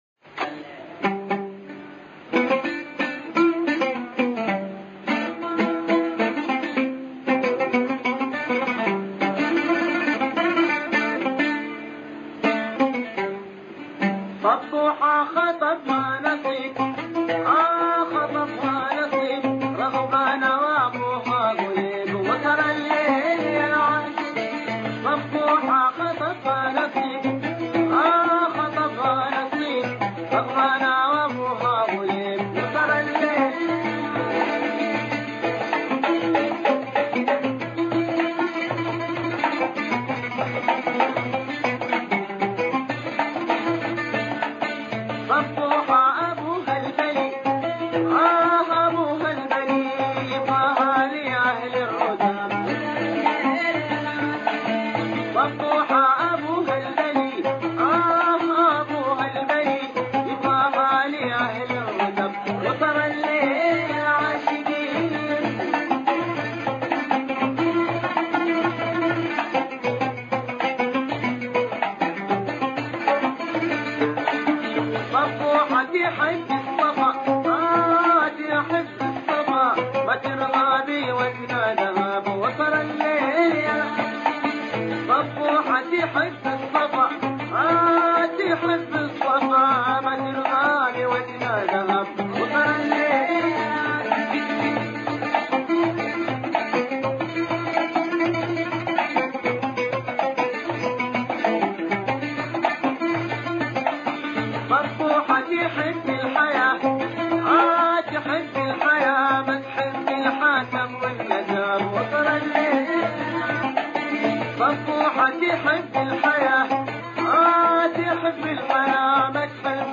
تسجيل صوتي قديم لأحد الفنانين اليمنيين